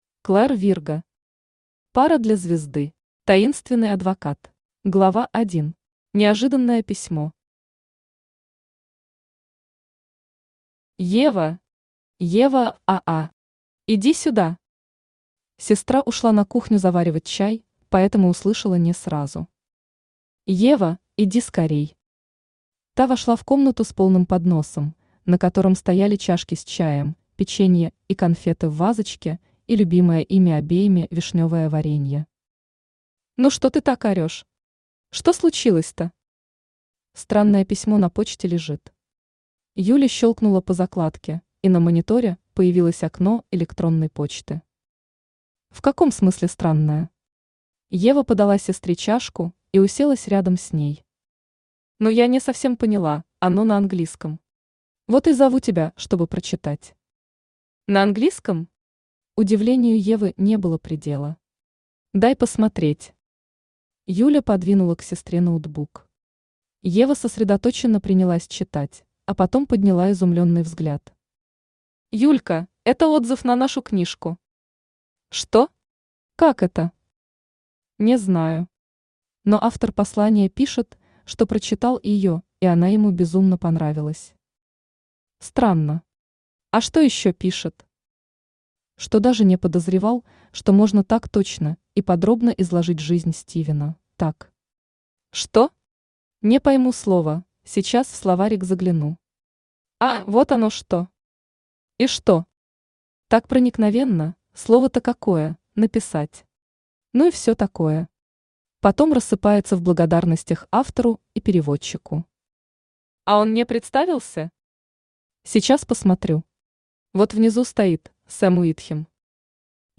Аудиокнига Пара для звезды.
Таинственный адвокат Автор Клэр Вирго Читает аудиокнигу Авточтец ЛитРес.